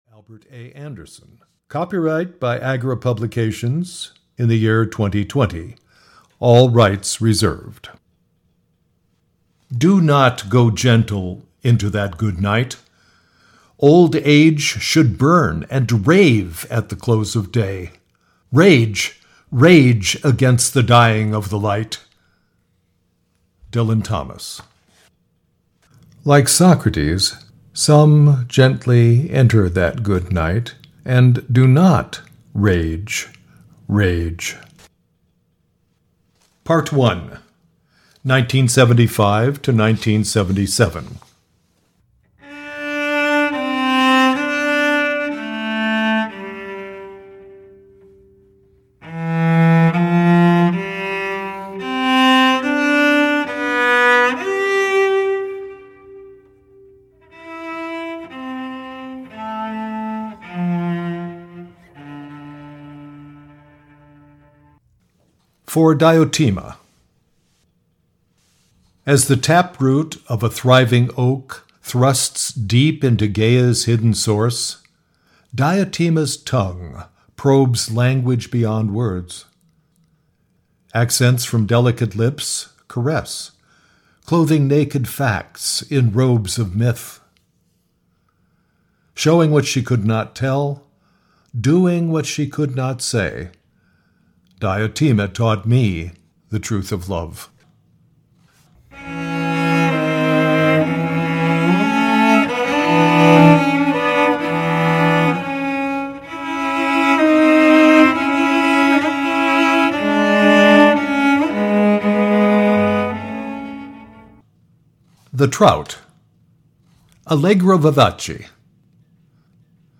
Fading of the Light: A Love Story (EN) audiokniha
Ukázka z knihy